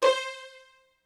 STR HIT C4.wav